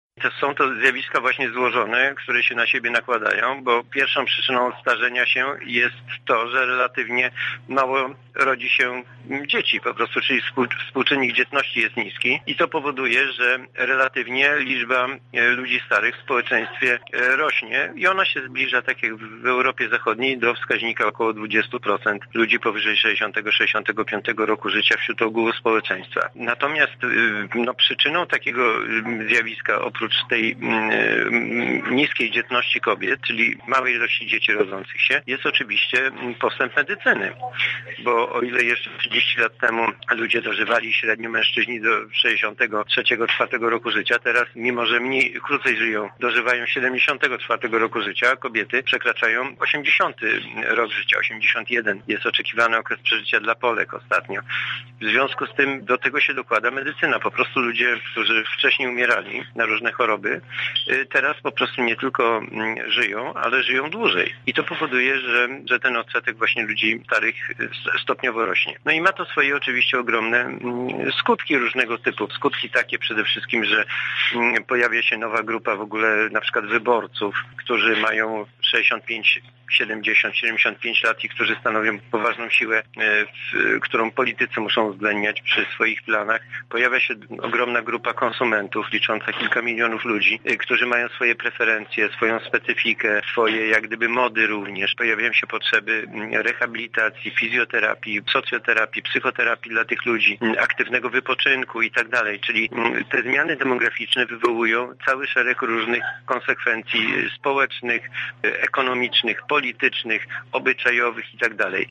socjolog
socjolog.mp3